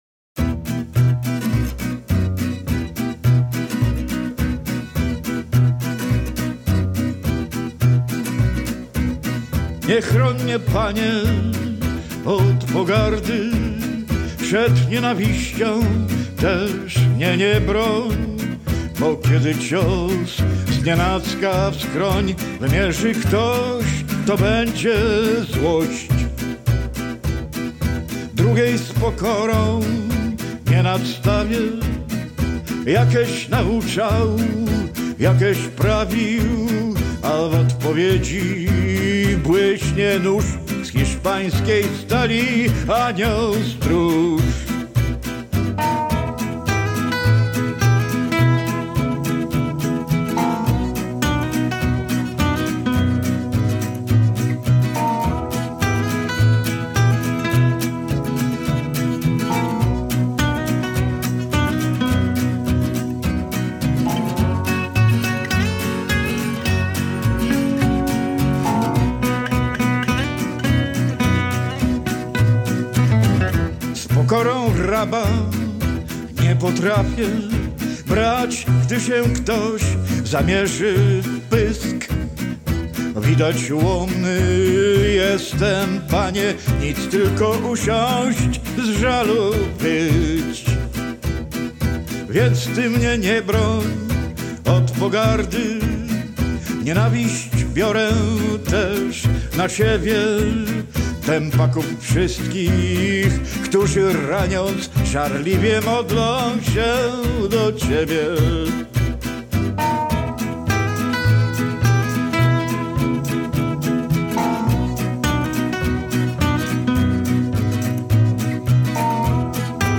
w Czuktach (gm. Kowale Oleckie)